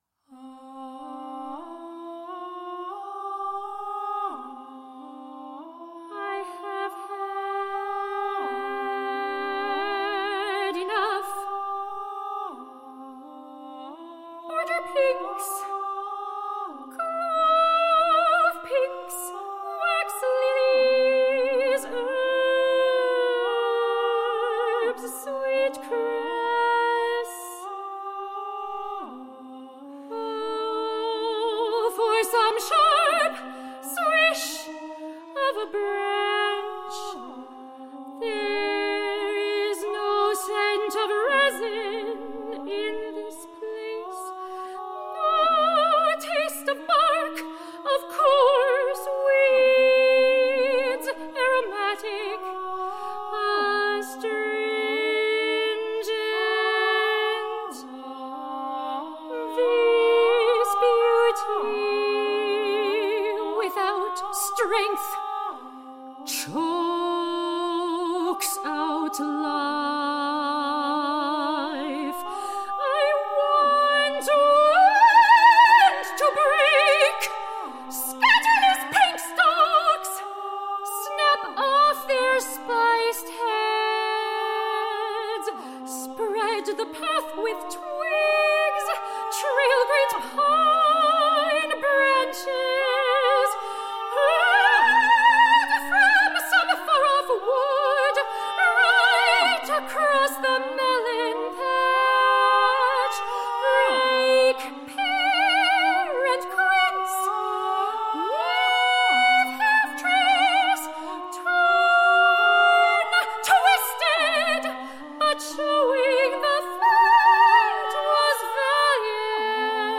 • Genres: Classical, Opera
soprano & violin
soprano & ukulele